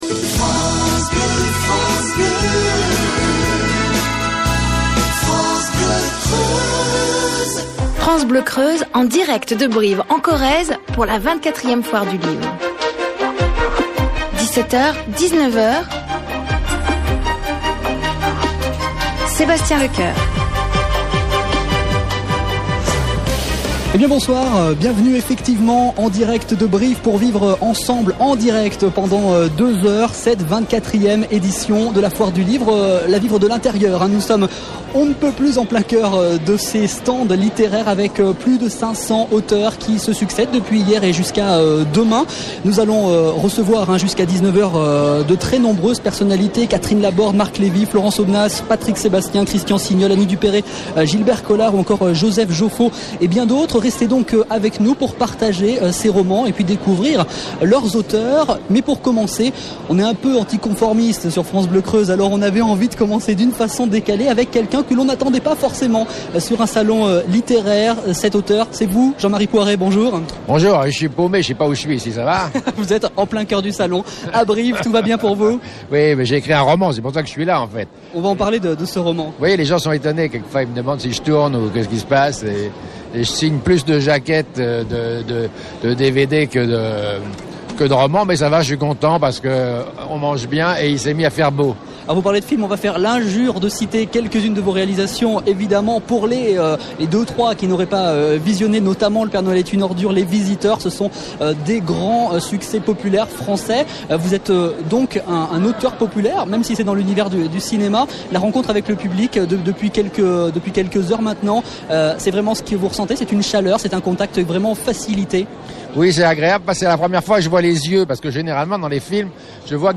exterieur.mp3